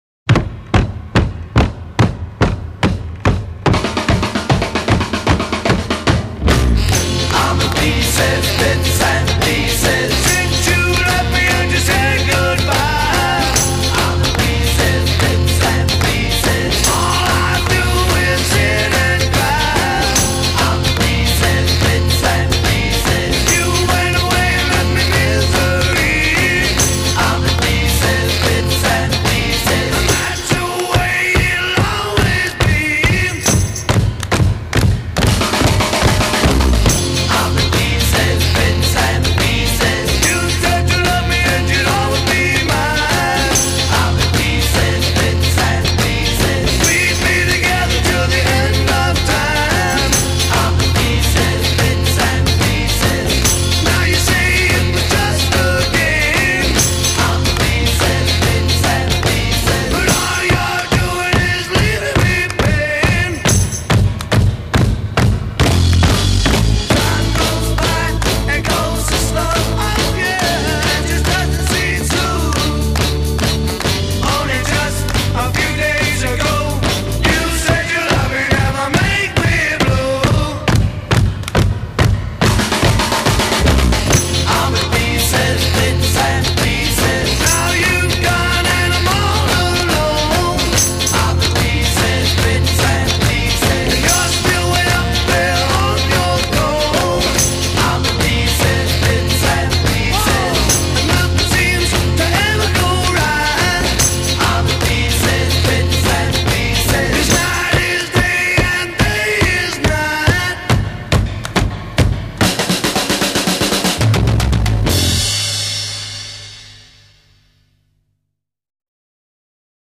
Recorded at Lansdowne Studios.
with static choral response